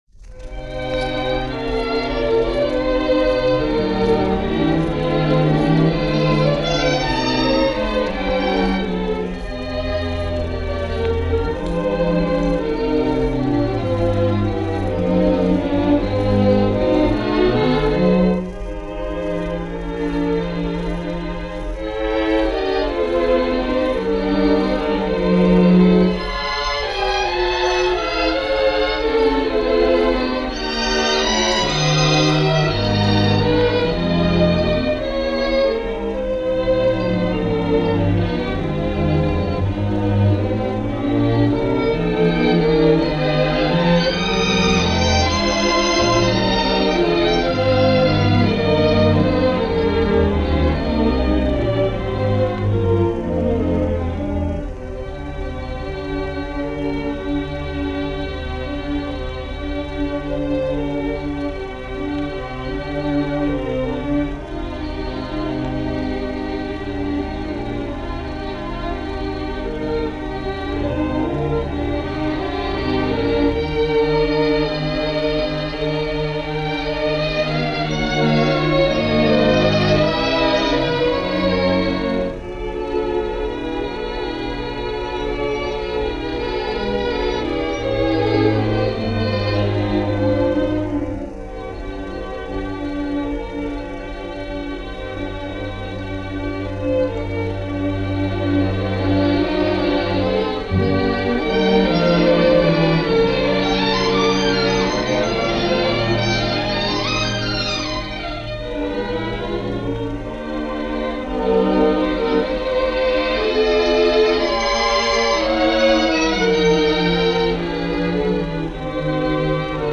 Peter Warlock: Serenade for Strings – New London String Ensemble – BBC Session
As with many recordings from this period, there appears to be pitch problems which are hard to correct, which may explain why this performance hasn’t been reissued in any form.
Warlock-Serenade-New-London-String-Ensemble-1945.mp3